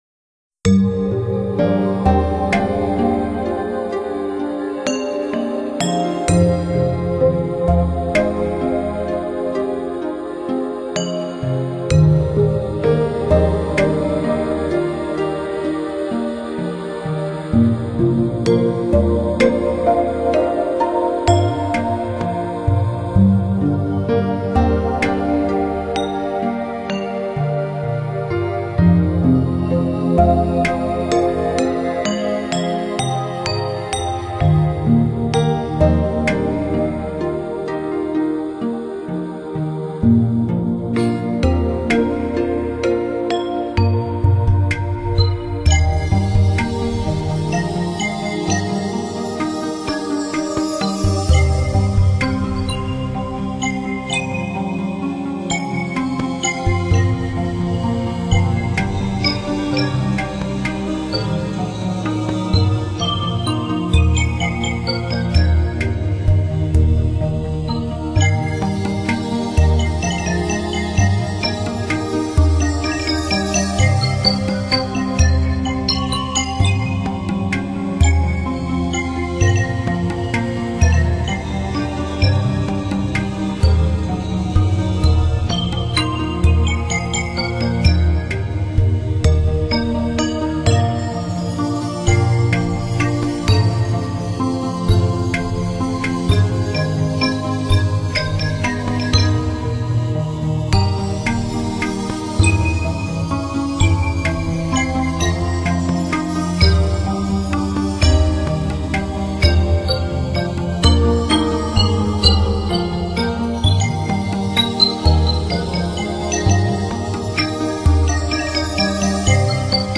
将数个不同文化的音乐元素融合在同一乐曲中，发展出一类崭新的乐种，